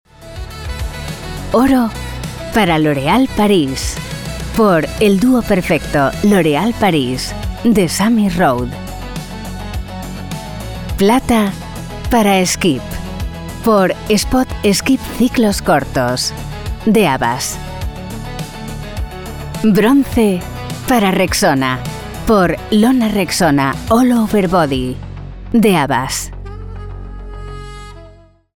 Veelzijdig, Zakelijk, Commercieel, Vriendelijk, Natuurlijk
Audiogids